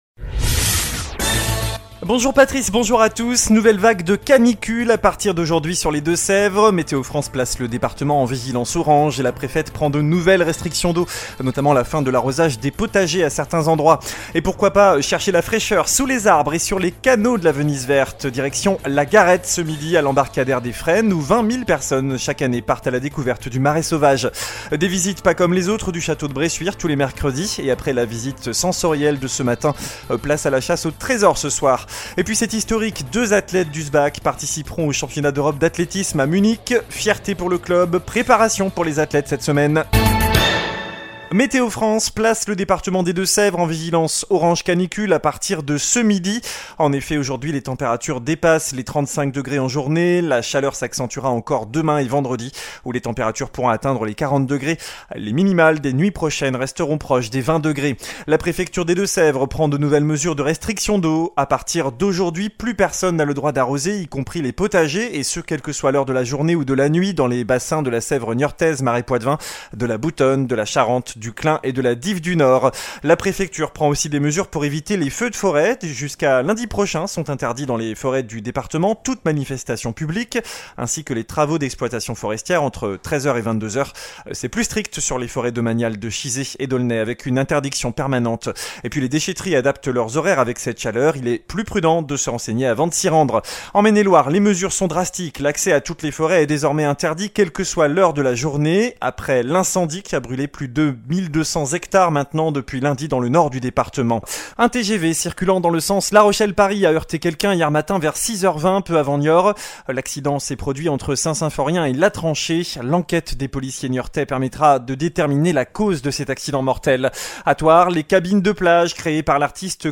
JOURNAL DU MERCREDI 10 AOÛT